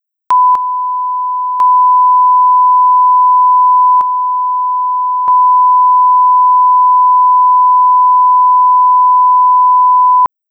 Простой тест атаки и релиза с визуализацией. Показывает реакцию на сигнал железки, и то, как это повторяют плагины.